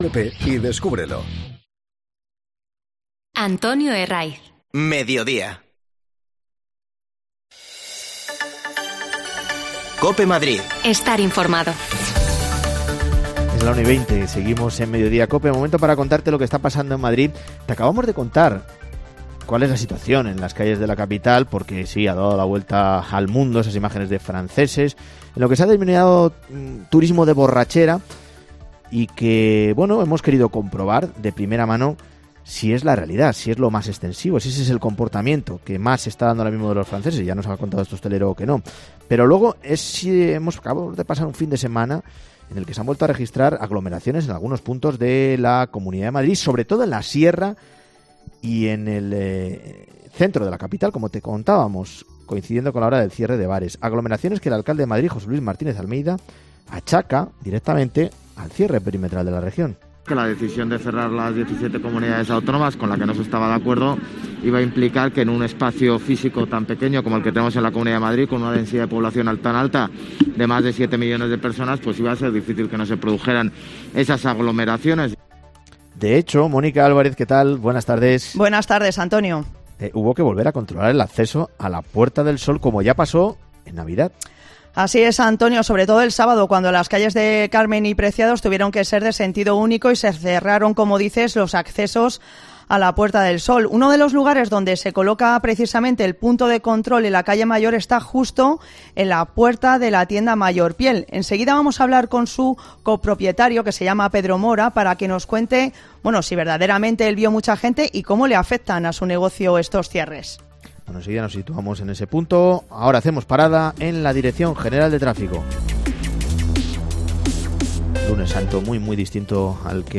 Hablamos con uno de ellos
Las desconexiones locales de Madrid son espacios de 10 minutos de duración que se emiten en COPE , de lunes a viernes.